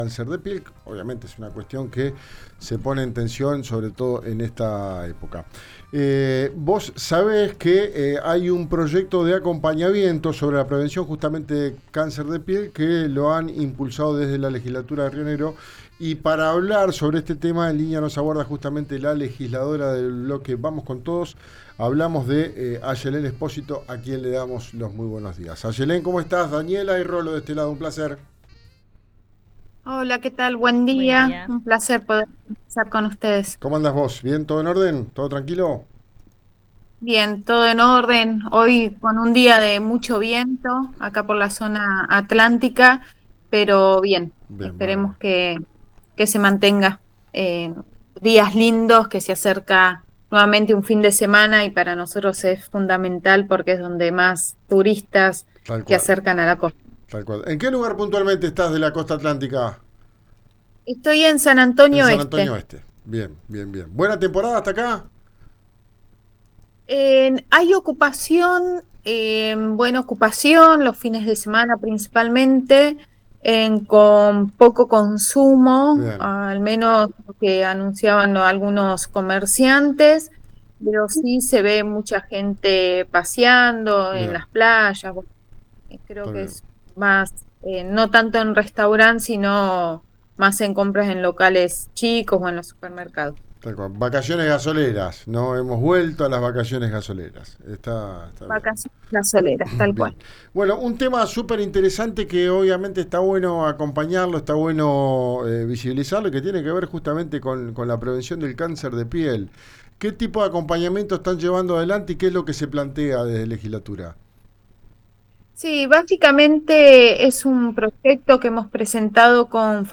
Escuchá a Ayelén Spósito en RIO NEGRO RADIO